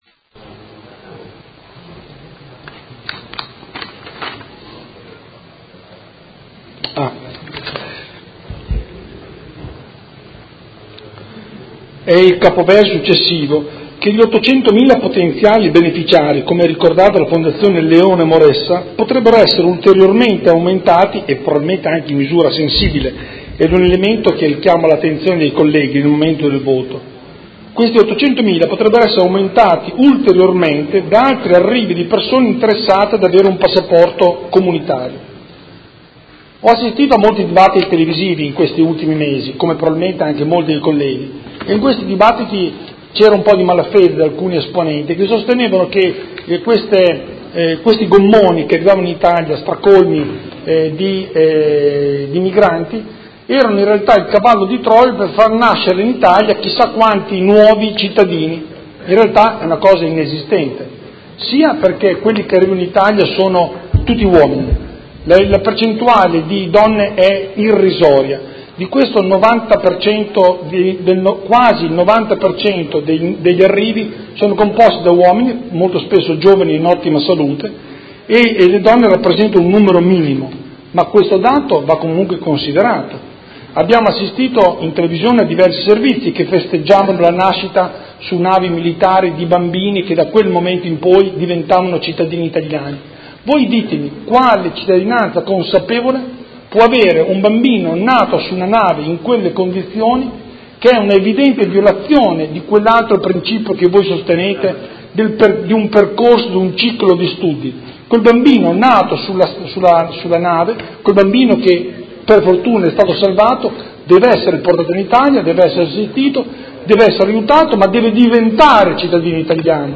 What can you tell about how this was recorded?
Seduta del 14/09/2017 Presenta emendamento 138742